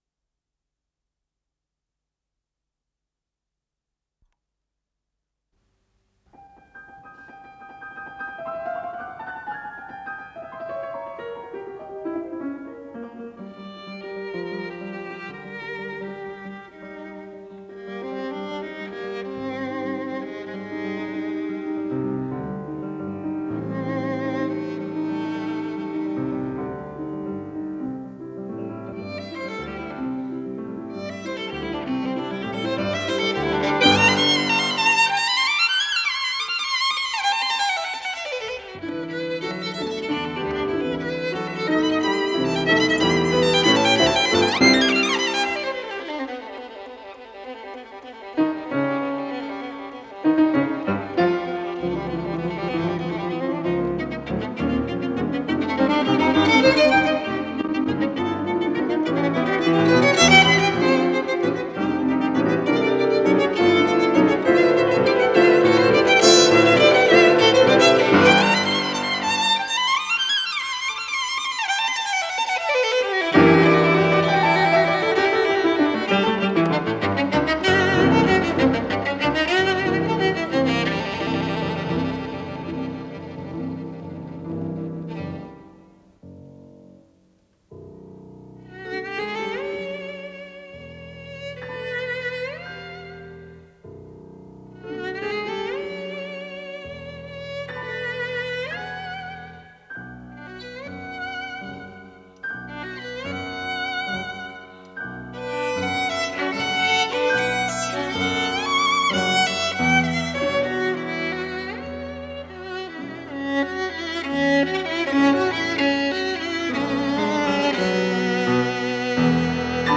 с профессиональной магнитной ленты
скрипка
фортепиано
Соль минор